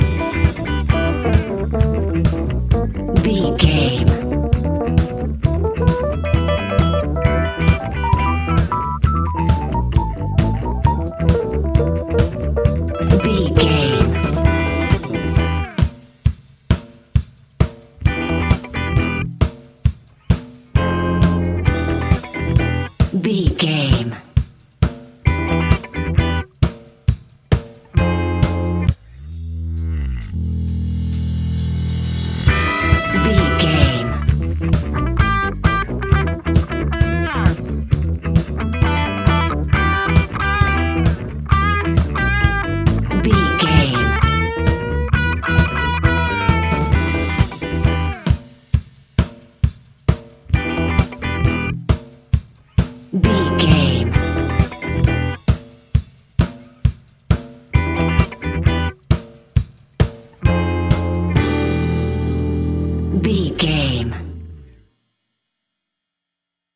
Ionian/Major
E♭
house
electro dance
synths
techno
trance
instrumentals